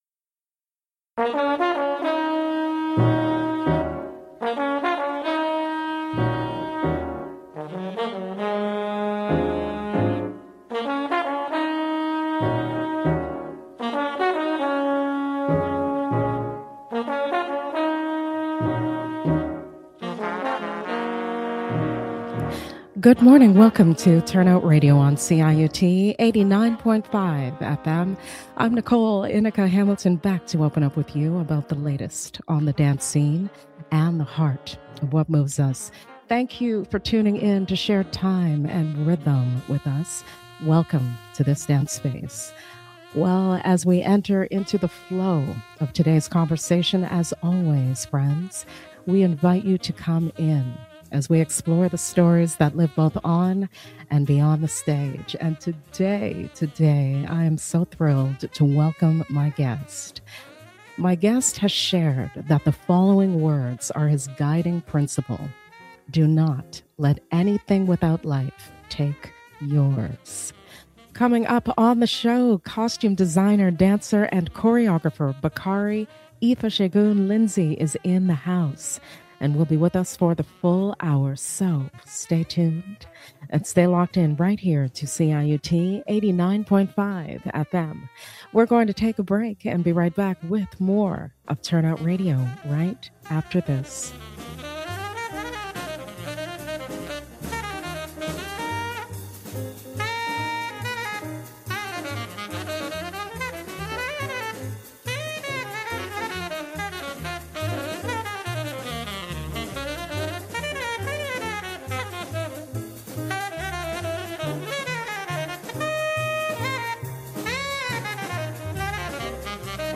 L ive on CIUT 89.5 FM